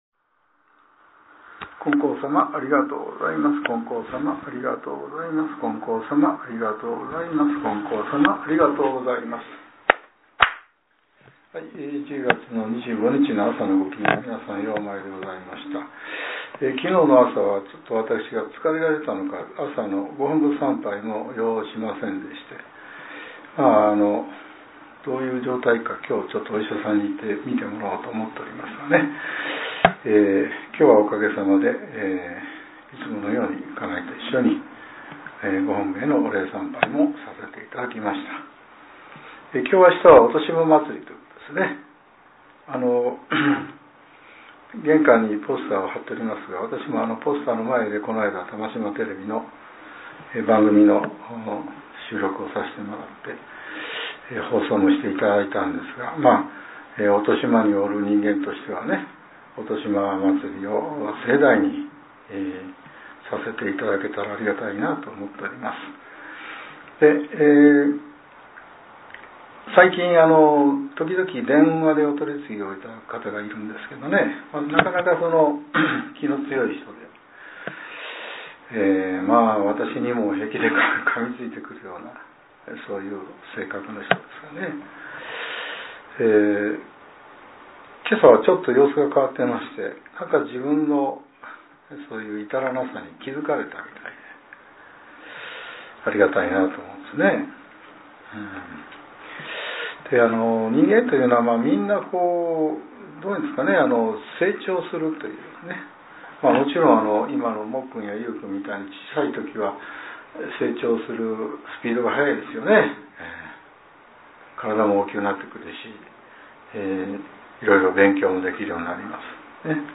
令和７年１０月２４日（朝）のお話が、音声ブログとして更新させれています。